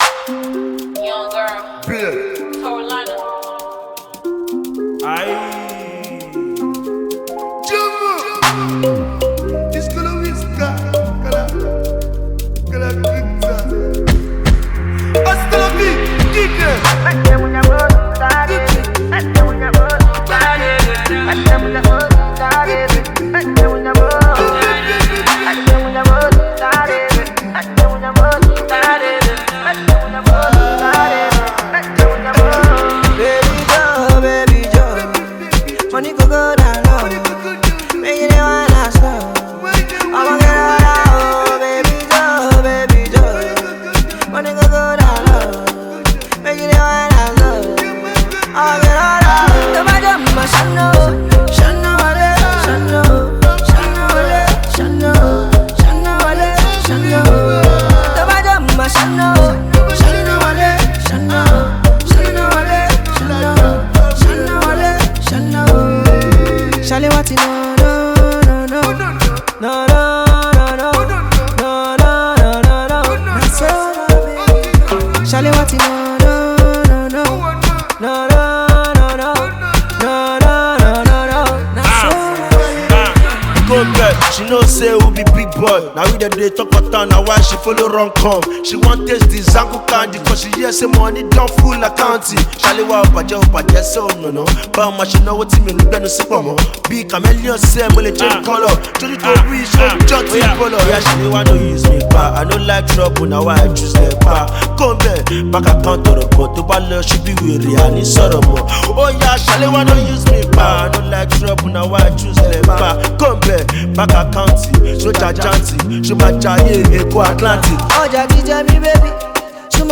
The new song which is so Groovy